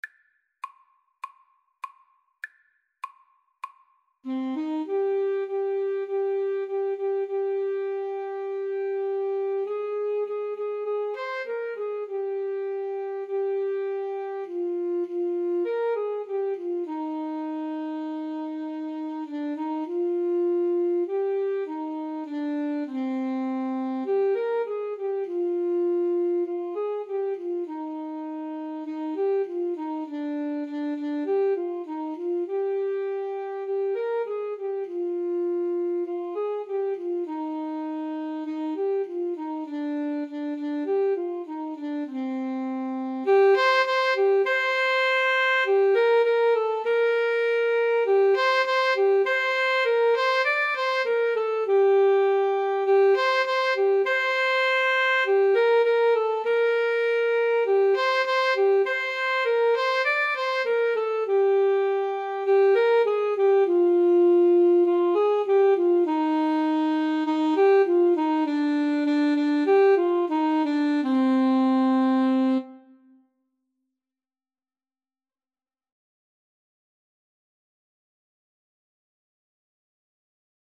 Free Sheet music for Alto Saxophone Duet
C minor (Sounding Pitch) G minor (French Horn in F) (View more C minor Music for Alto Saxophone Duet )
Moderato
4/4 (View more 4/4 Music)
Traditional (View more Traditional Alto Saxophone Duet Music)
world (View more world Alto Saxophone Duet Music)